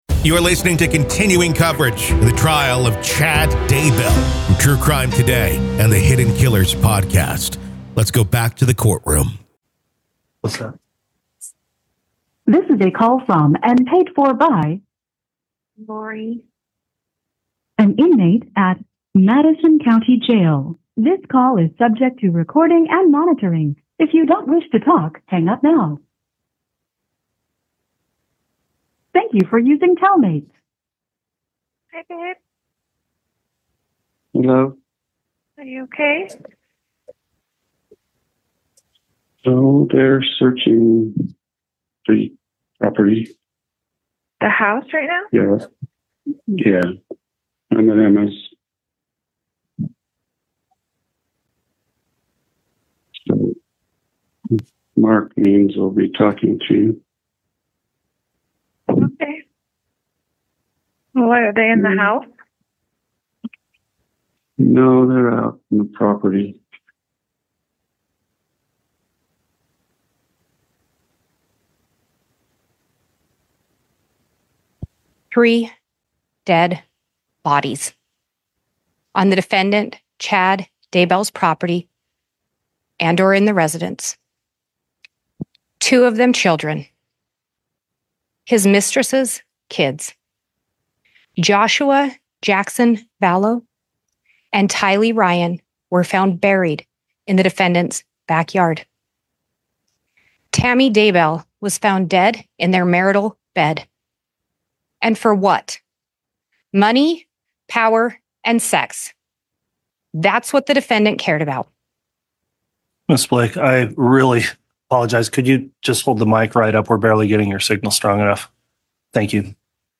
State Closing Arguments ID v Chad Daybell, Doomsday Prophet Murder Trial PART 1